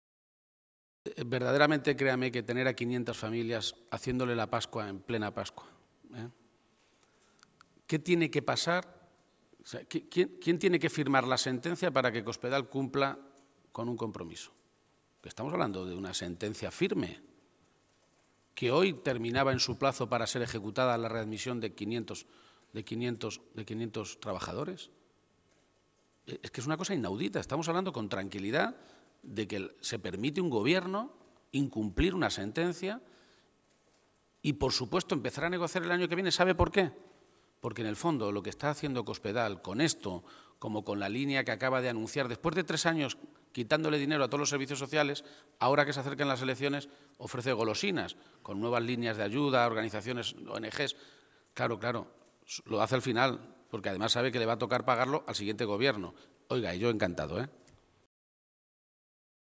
García-Page se pronunciaba de esta manera esta mañana, en Toledo, a preguntas de los medios de comunicación en relación a la reunión que Gobierno regional y sindicatos mantenían a la misma ahora para ver el cumplimiento de un fallo del Tribunal Supremo que ratifica la ilegalidad del despido de unos 500 empleados públicos en agosto del año 2012 y obliga a su reincorporación a la administración regional.
Cortes de audio de la rueda de prensa